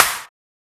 SNARE0000.wav